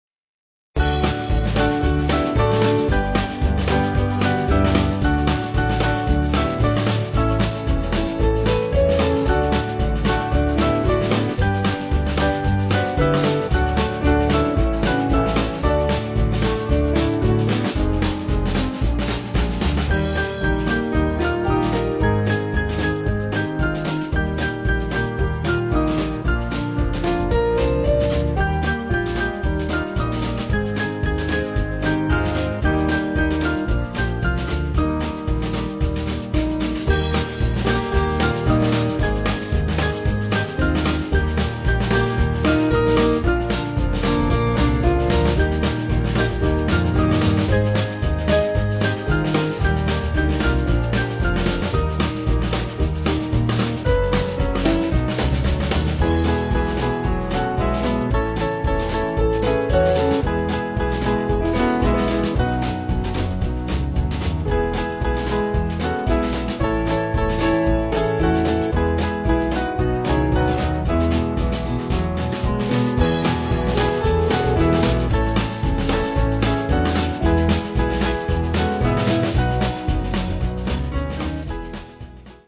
Small Band
(CM)   3/D-Eb-E-F